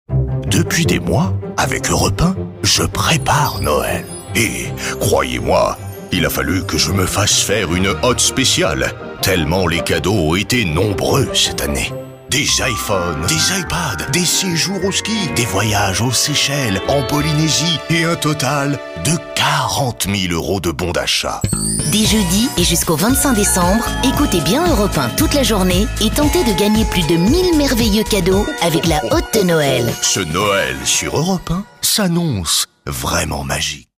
Voix du Père Noël
Autopromo pour Europe 1 et la hotte de Noël.
Voix off homme très grave pour interpréter le père Noël.
Ma voix se devait d’être bien ronde et très chaleureuse.
Pere-Noel-dEurope-1-1.mp3